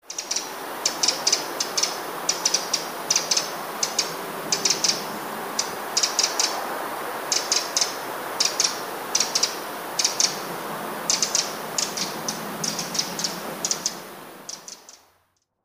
Śpiew strzyżyka układa się w łatwo zauważalny wzorzec.
79strzyzyk.mp3